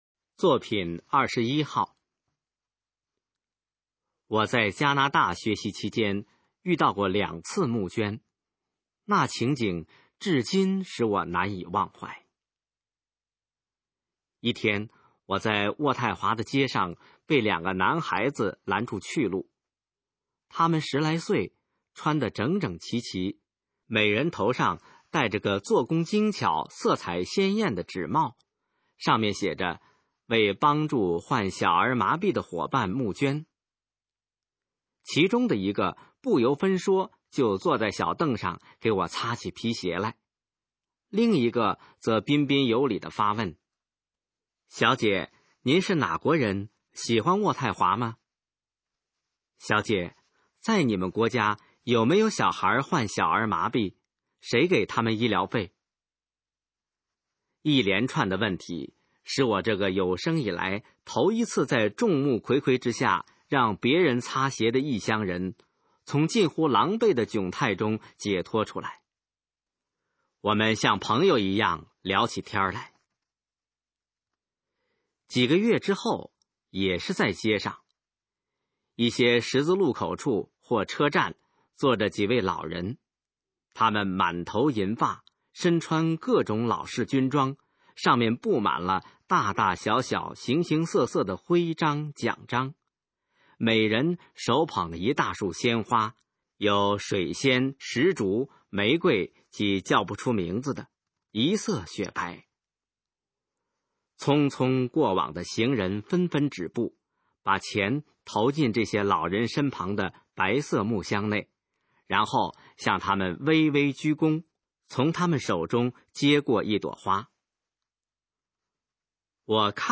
《捐诚》示范朗读_水平测试（等级考试）用60篇朗读作品范读